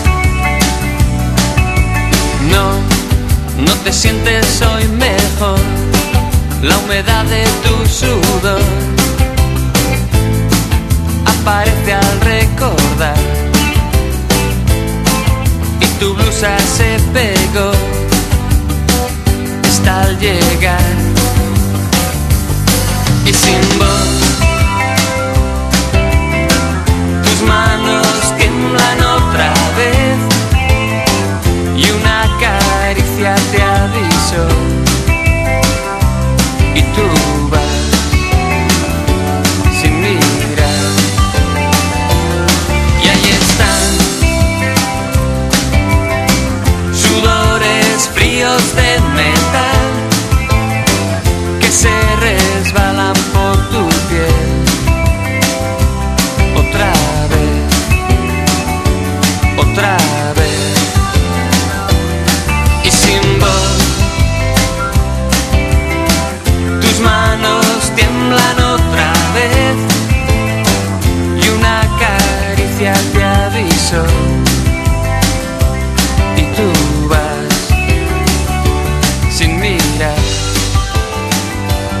HARMONY POP
ぬるま湯トロピカル・ポップ